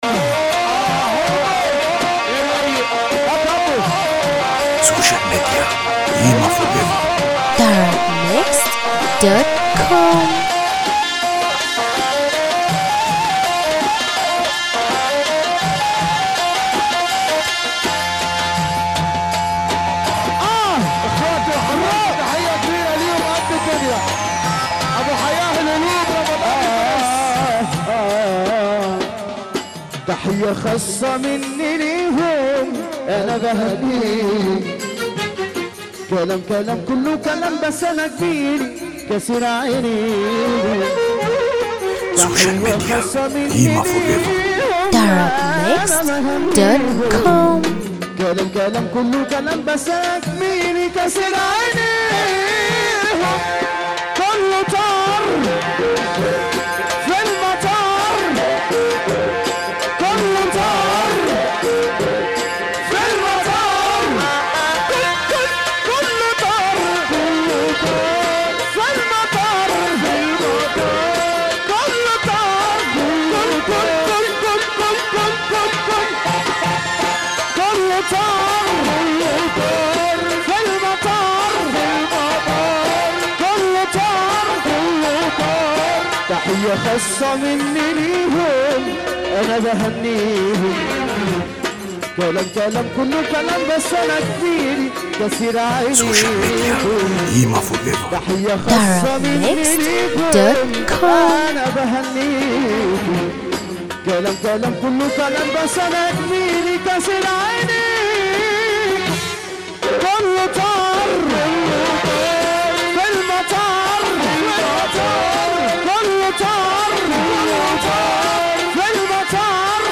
موال